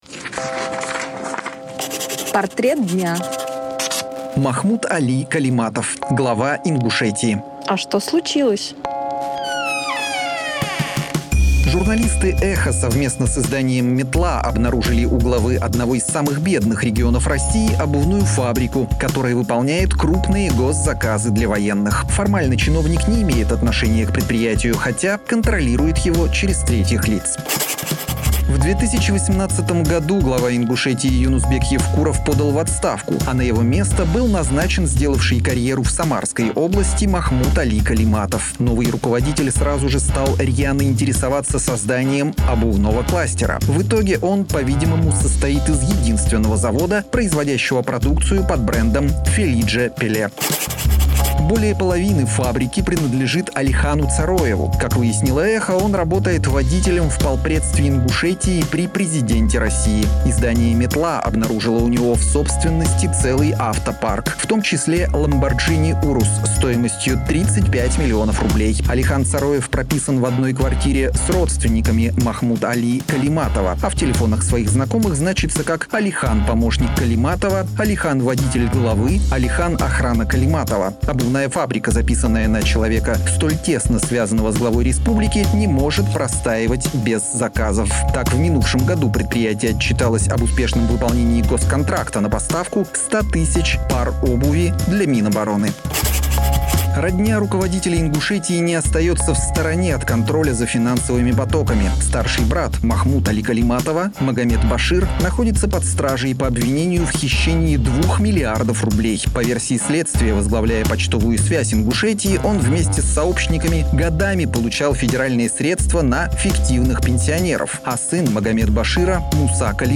Читает